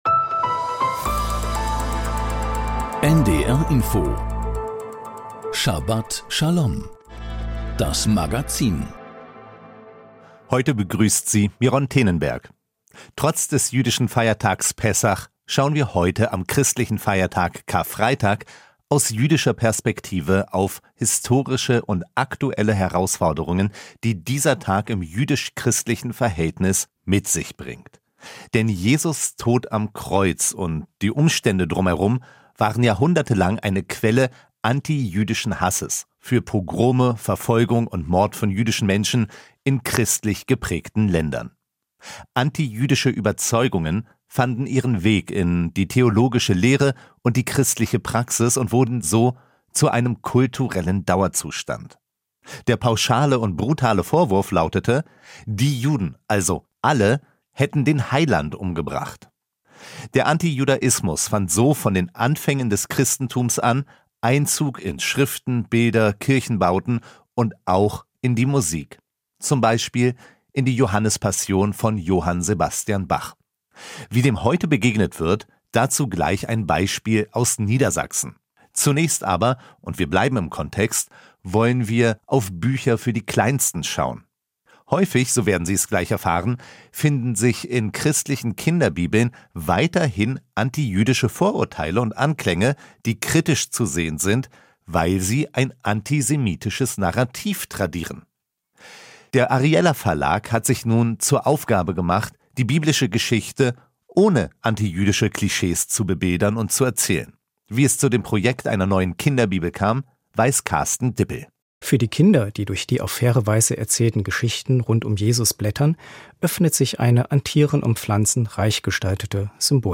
Das Magazin Schabat Schalom berichtet aus dem jüdischen Leben mit Nachrichten, Interviews, Berichten und Kommentaren. Dazu die Wochenabschnittsauslegung der Thora.